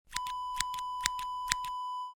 Scissors snip sound effect .wav #8
Description: The sound of a pair of scissors snipping
A beep sound is embedded in the audio preview file but it is not present in the high resolution downloadable wav file.
Keywords: scissors, snip, snipping, cut, cutting, hair, click, clicking
scissors-snip-preview-8.mp3